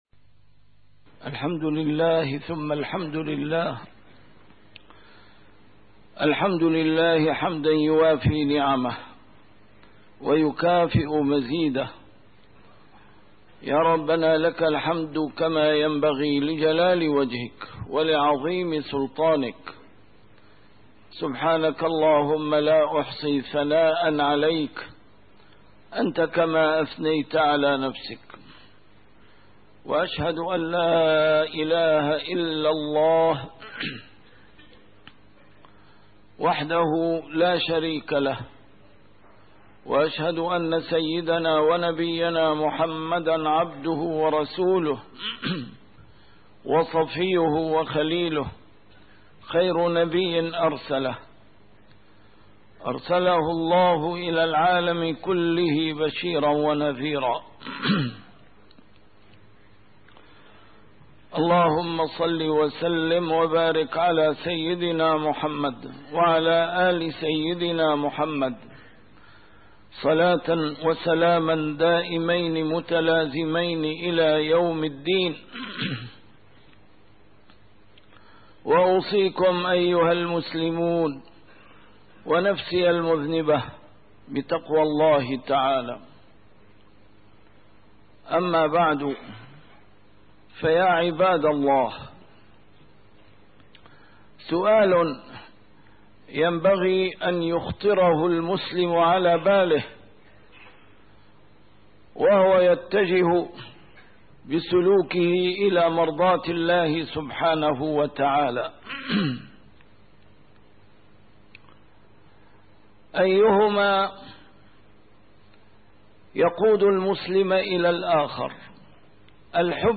A MARTYR SCHOLAR: IMAM MUHAMMAD SAEED RAMADAN AL-BOUTI - الخطب - السبيل إلى محبة الله عز وجل